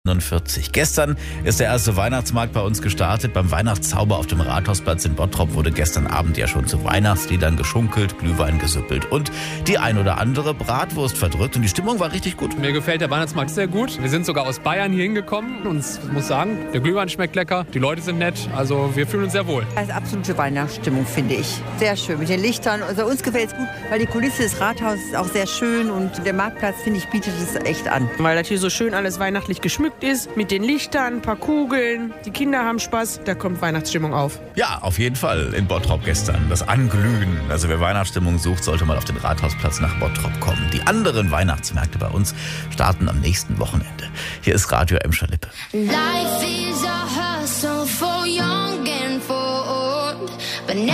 Gestern ist ja der erste Weihnachtsmarkt bei uns gestartet. Beim Weihnachtszauber auf dem Rathausplatz in Bottrop wurde gestern Abend ja schon zu Weihnachtsliedern geschunkelt, Glühwein gesüppelt und die eine oder andere Bratwurst verdrückt. Und die Stimmung war richtig gut!